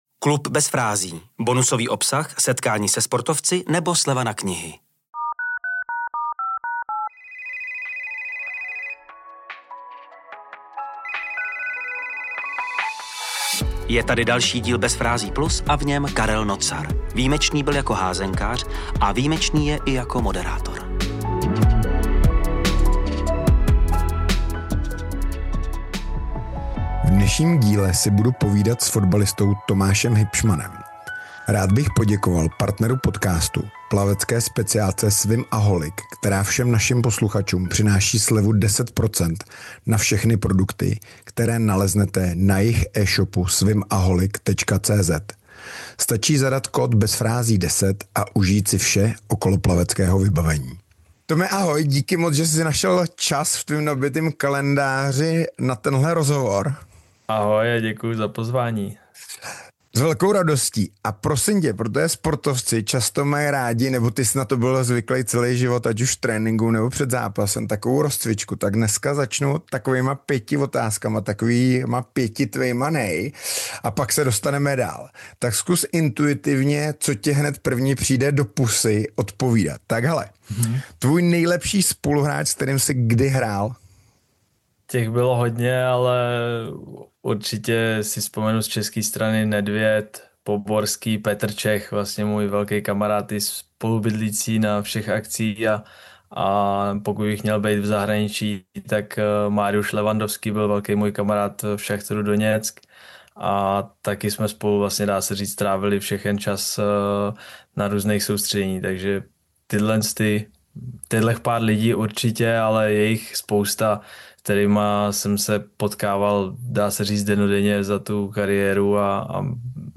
🎧 CELÝ ROZHOVOR pouze pro členy KLUBU BEZ FRÁZÍ.